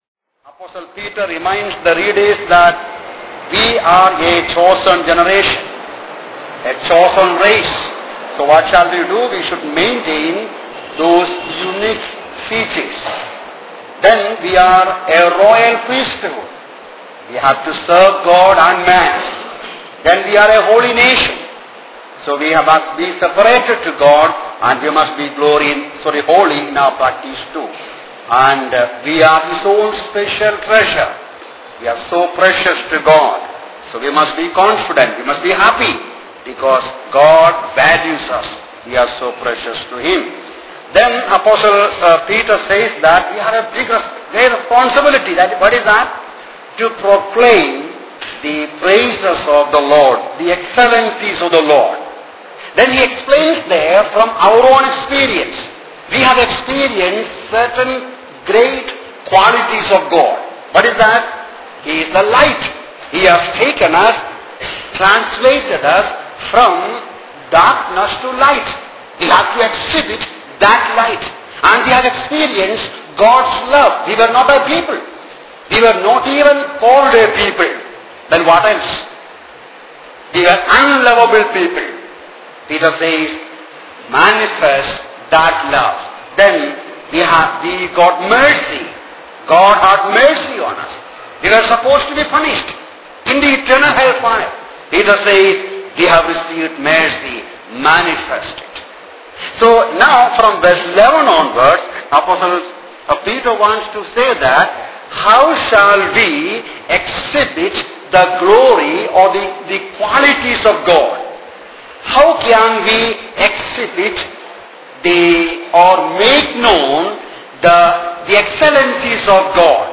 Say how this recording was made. for a series of Bible classes held at Bethel Christian Assembly, Santacruz, Mumbai in 2010